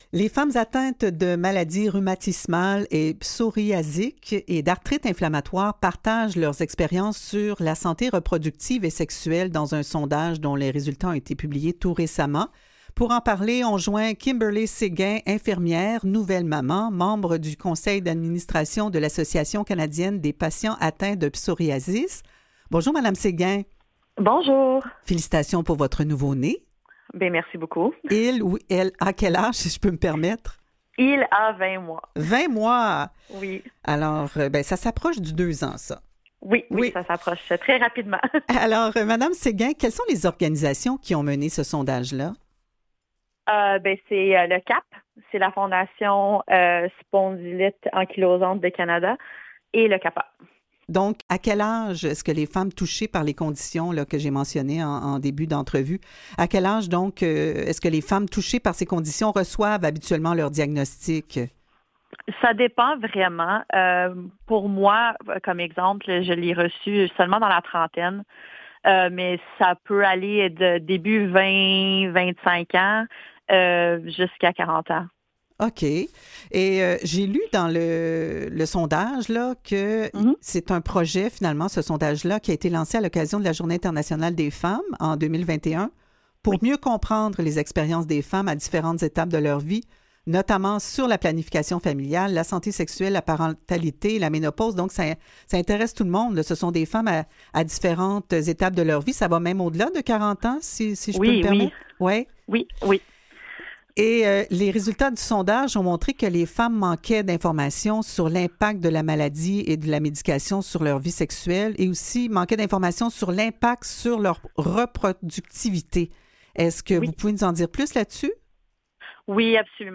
Revue de presse et entrevues du 5 octobre 2021